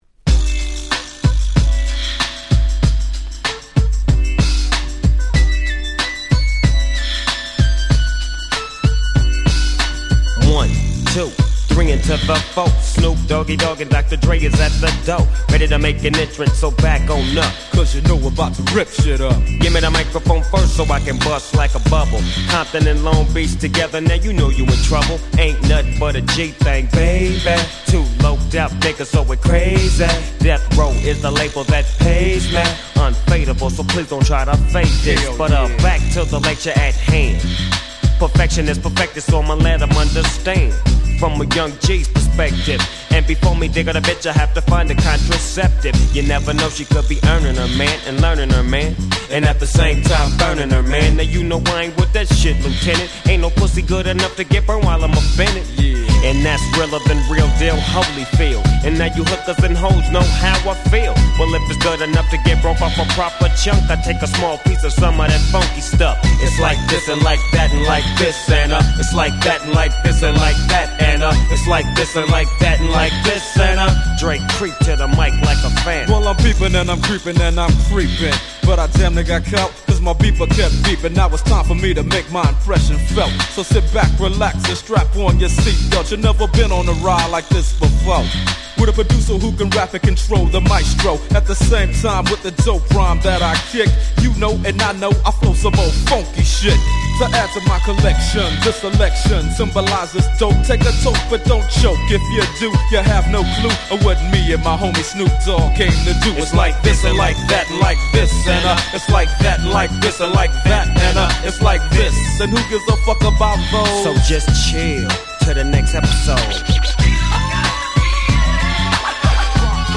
93' Big Hit West Coast Hip Hop.